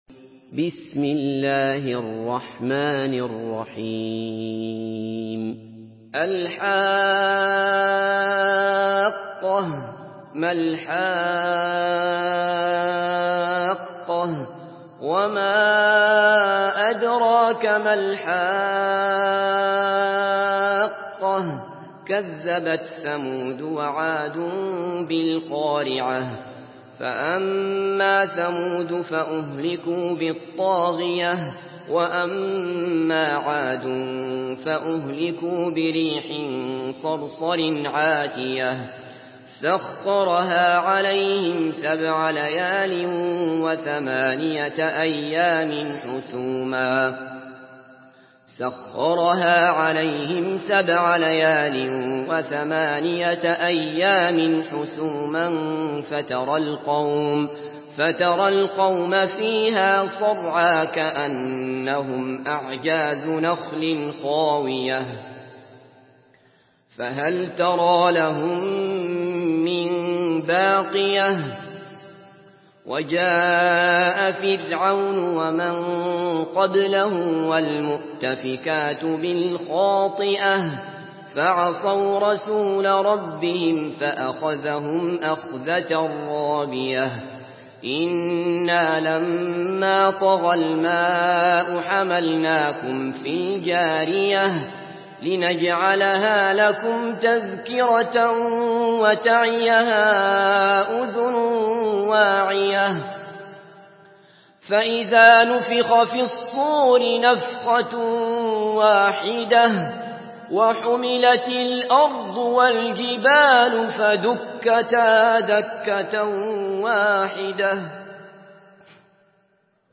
Surah Hakka MP3 by Abdullah Basfar in Hafs An Asim narration.
Murattal